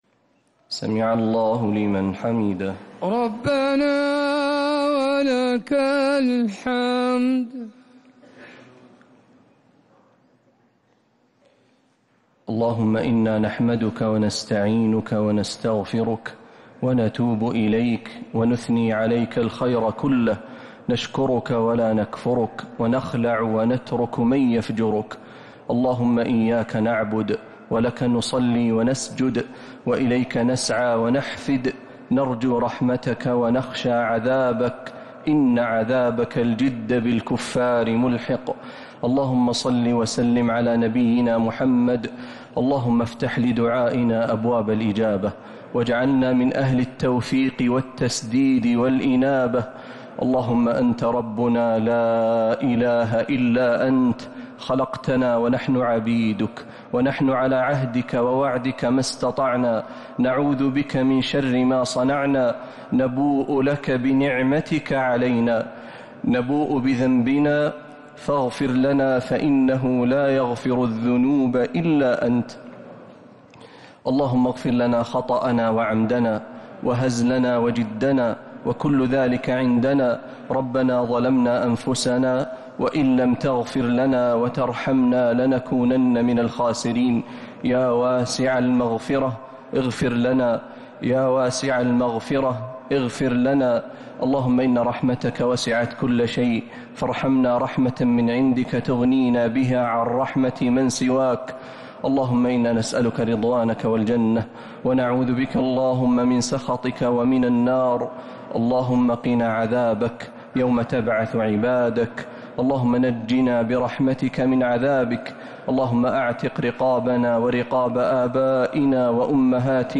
دعاء القنوت ليلة 5 رمضان 1447هـ | Dua 5th night Ramadan 1447H > تراويح الحرم النبوي عام 1447 🕌 > التراويح - تلاوات الحرمين